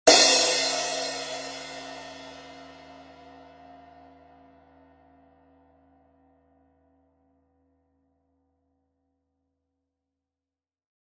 Zildjian 16" Medium Thin Crash Cymbal
16" A Zildjian Medium Thin Crash - A0230 16" A Zildjian Medium Thin Crash- Product Spotlight The A Medium Thin Crashes are bright and smooth. They are stronger than the thin crashes and have a higher pitch....